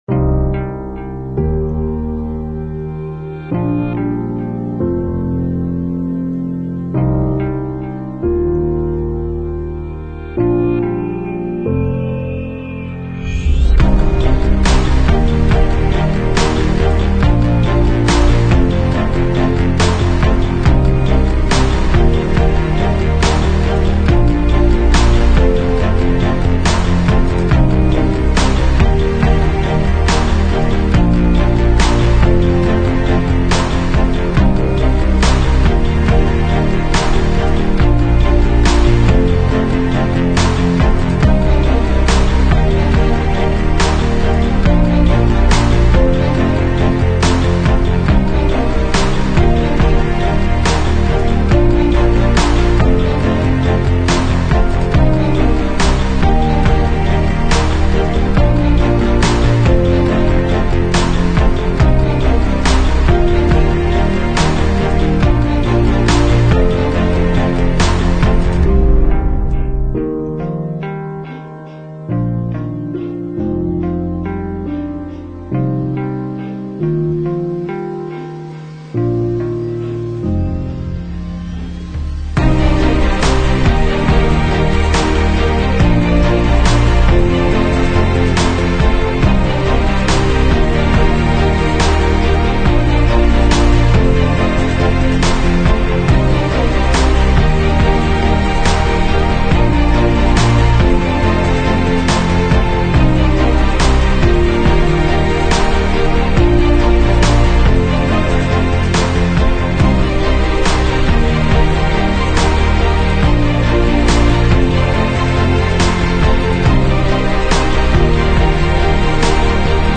Sample Rate 采样率16-Bit Stereo 16位立体声, 44.1 kHz